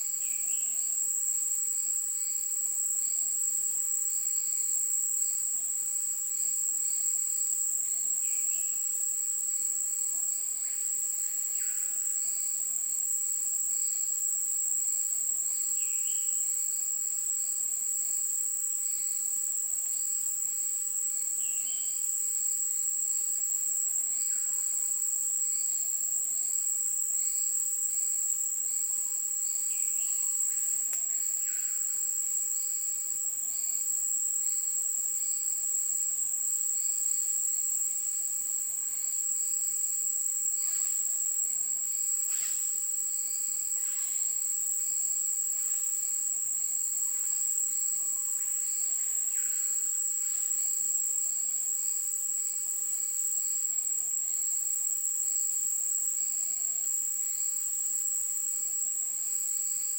Já são mais de 16 mil minutos gravados da vida na Floresta Nacional de Carajás, localizada no sudeste do Pará.
Aparelhos programados para gravar 24 horas foram fixados em árvores a aproximadamente dois metros do solo, a fim de coletar os sons.
Foi utilizado o gravador Audiomoth 1.0.0 e o R, uma linguagem de programação para computação estatística, para analisar os sons.